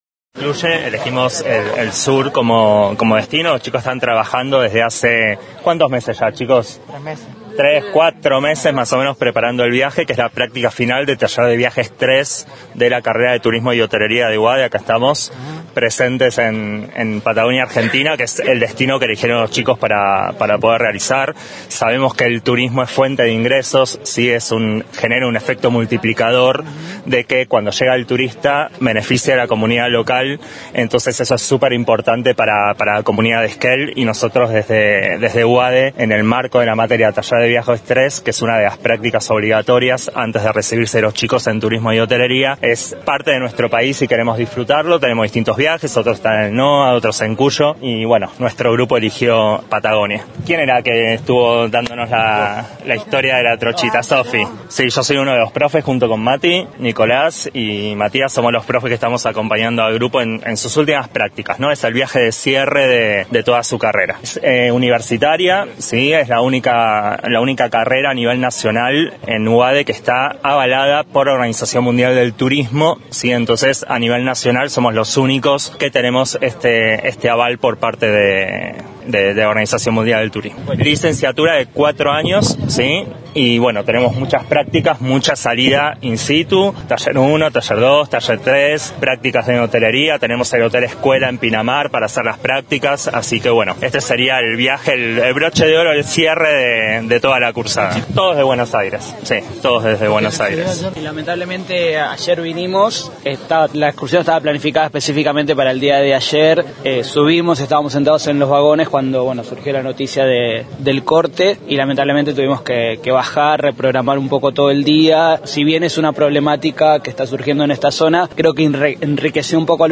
Un grupo de estudiantes de la carrera de turismo y hotelería en la UADE (Universidad Argentina de la Empresa) visitan Esquel y la zona, en el marco del trabajo final de la materia taller de viajes III. Los docentes de este grupo de estudiantes conversaron con los medios de comunicación, al momento de visitar la estación de La Trochita.